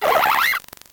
Cri de Mateloutre dans Pokémon Noir et Blanc.